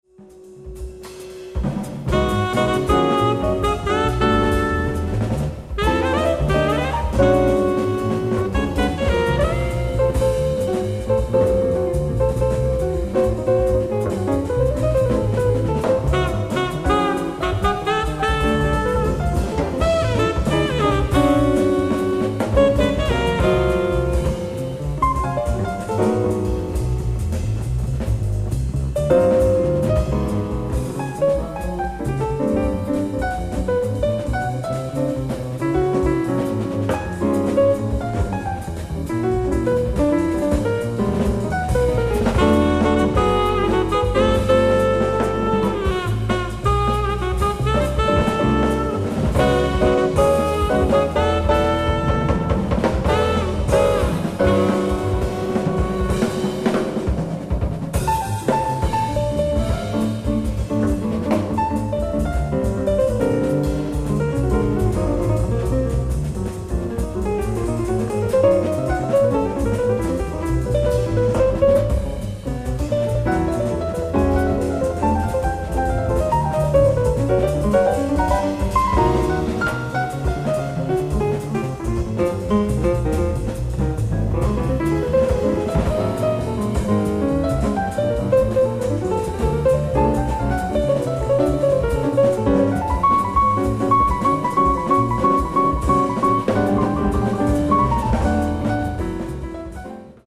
※試聴用に実際より音質を落としています。